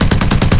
Sci-Fi Weapons
sci-fi_weapon_auto_turret_loop.wav